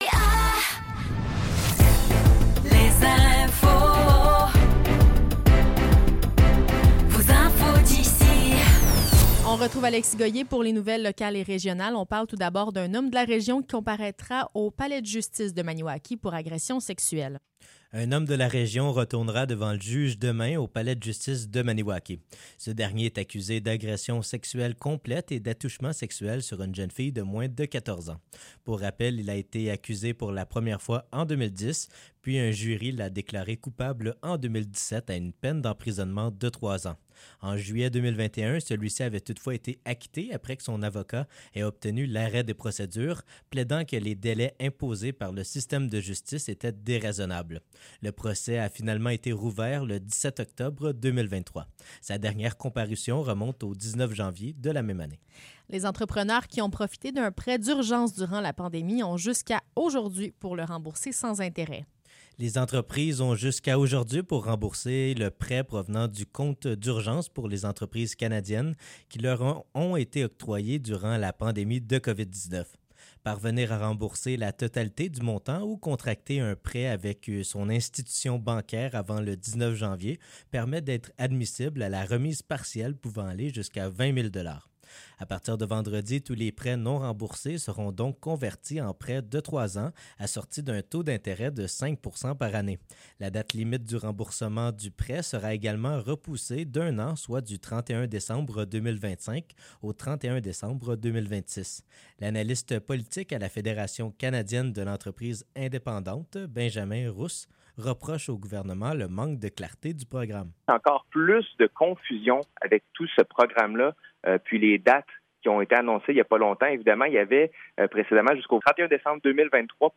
Nouvelles locales - 18 janvier 2024 - 16 h